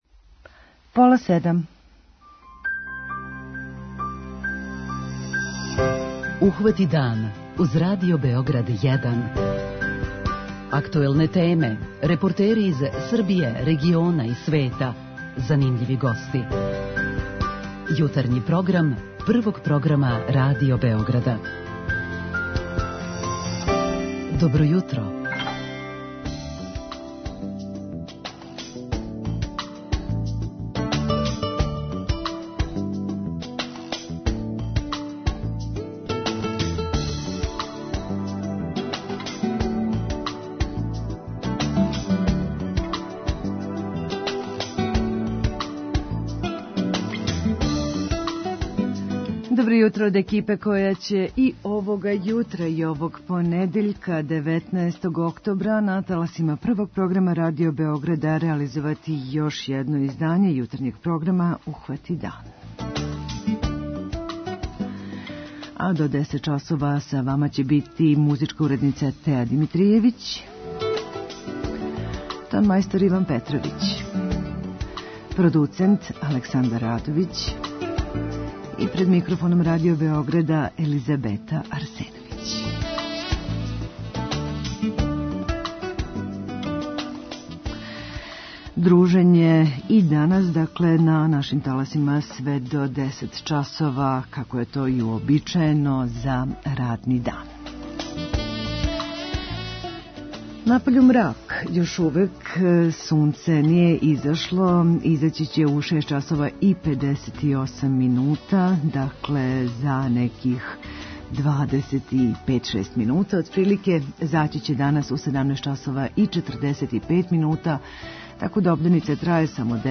Репортер Јутарњег програма убрзо потом јавиће се и са платоа испред Београдске арене која од данас поново постаје привремена ковид болница, спремна да прихвати све лакше оболеле којима је ипак неопходна болничка нега.